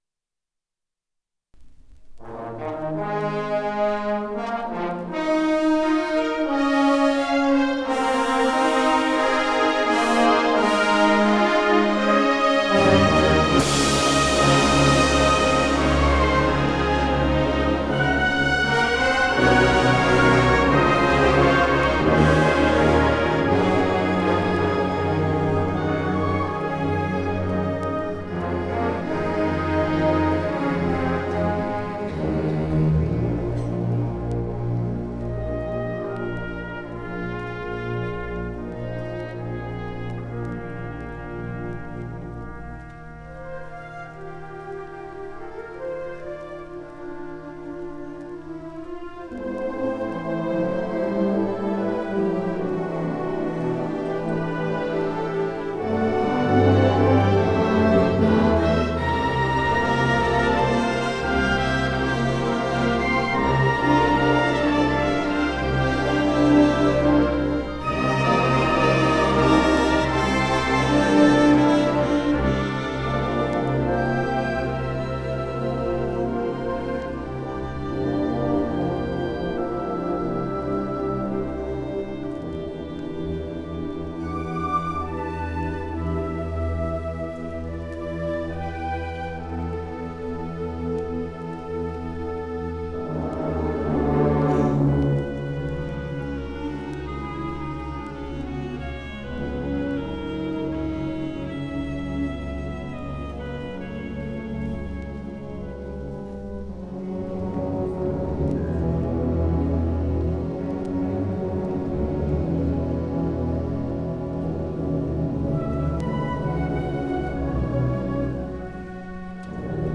1979 Music in May band performance recording · Digital Exhibits · heritage
2986d56c8e89a69113d23455a24b85719ceec83d.mp3 Title 1979 Music in May band performance recording Description An audio recording of the 1979 Music in May band performance at Pacific University.
It brings outstanding high school music students together on the university campus for several days of lessons and events, culminating in the final concert that this recording preserves.